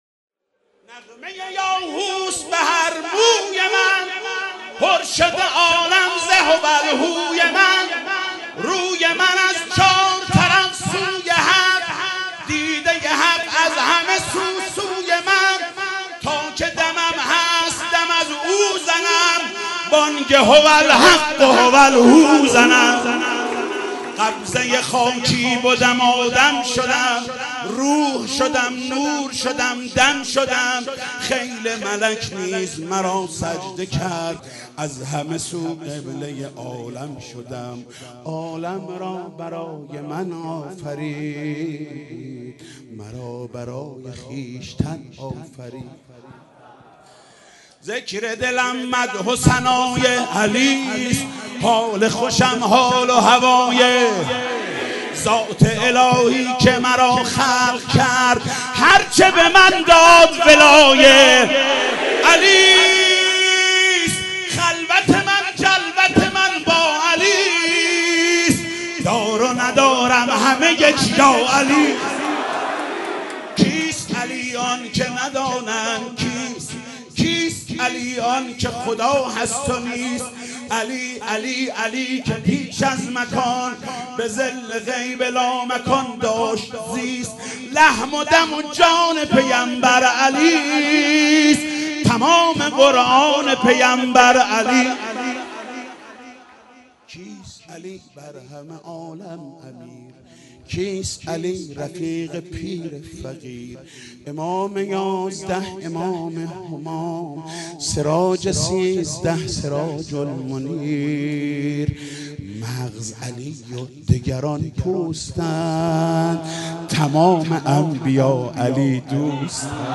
مدح: دار و ندارم همه یک یا علی است